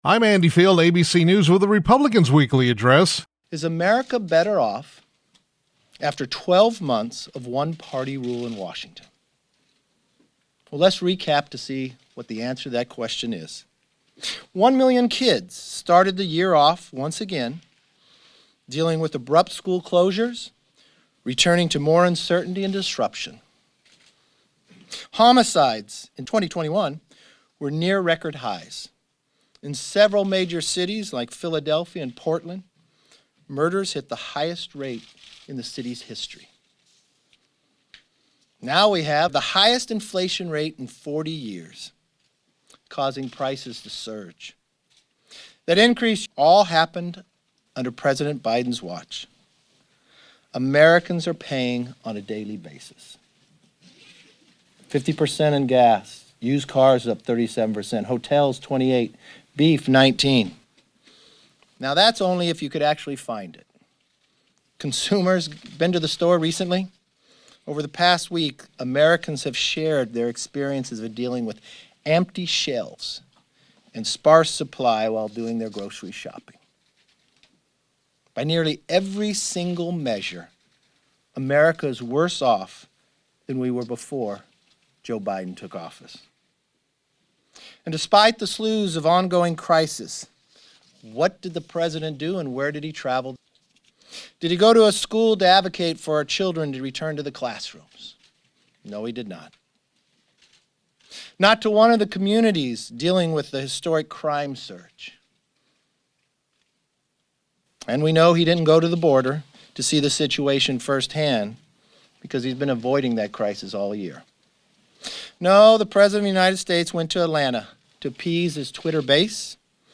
House Republican Leader Kevin McCarthy (CA-23) issued a statement on Democrats’ failures.
Here are his words: